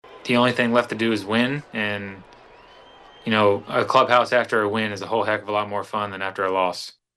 Lowe held a video chat with reporters yesterday and said he is looking forward to splashing a few balls into the Allegheny River.